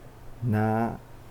スピーカ~な（竹富方言）